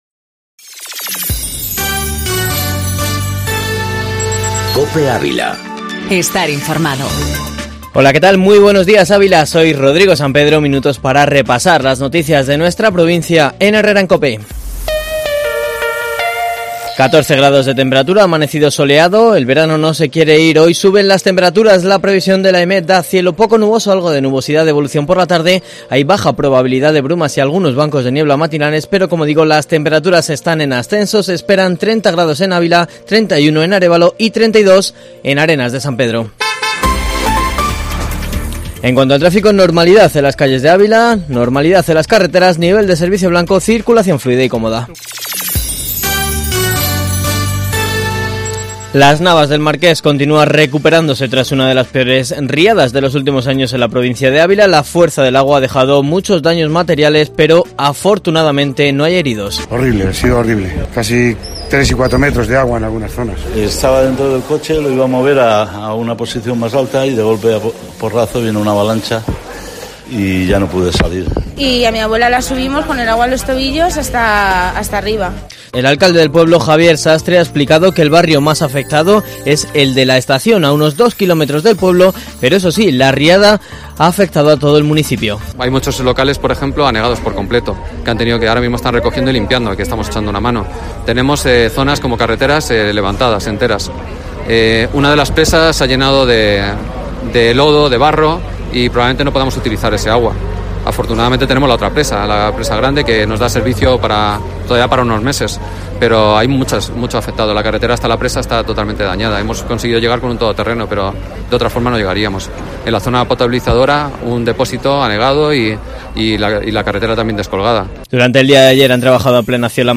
Informativo matinal Herrera en COPE Ávila 28/08/2019